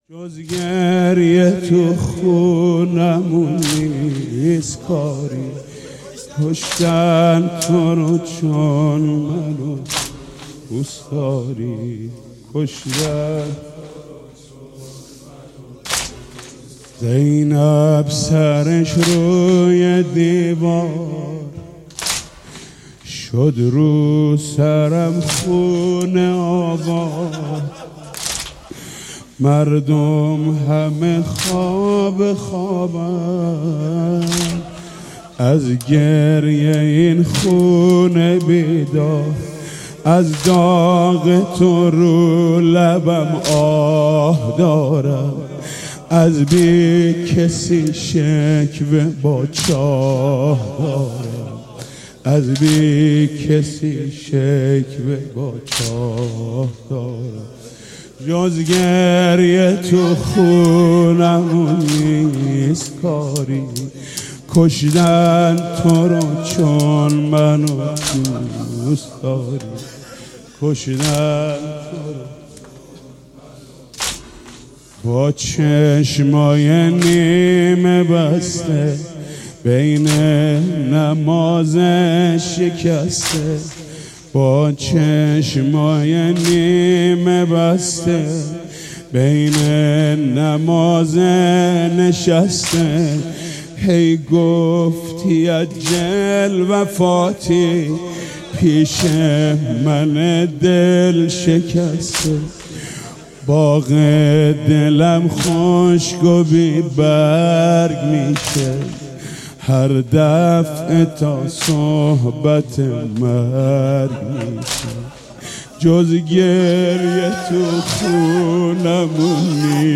سینه زنی؛ جز گریه تو خونمون نیست کاری...
🎧 بشنویم | به مناسبت ایام فاطمیه